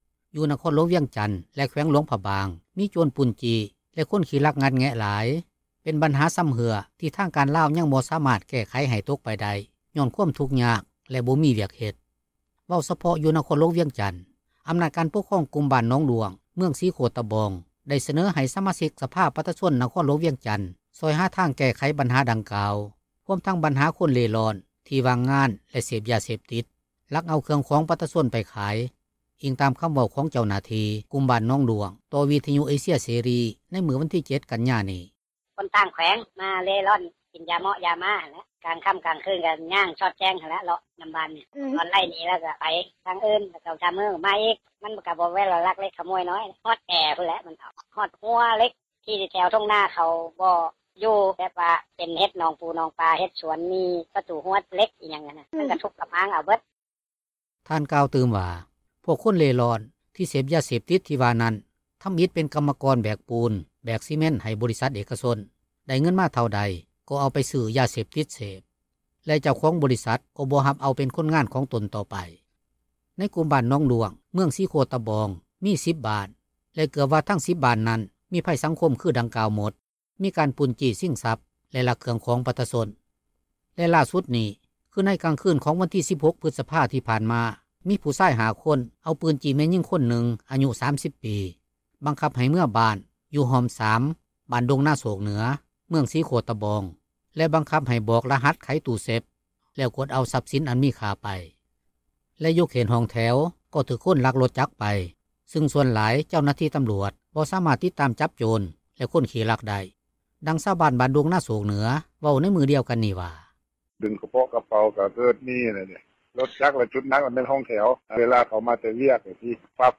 ດັ່ງຊາວບ້ານບ້ານດົງນາໂຊກເໜືອ ເວົ້າໃນມື້ດຽວກັນນັ້ນ ວ່າ:
ດັ່ງເຈົ້າໜ້າທີ່ ຕໍາຣວດປ້ອງກັນຄວາມສງົບ ແຂວງຫລວງພຣະບາງ ກ່າວໃນມື້ດຽວກັນນີ້ວ່າ: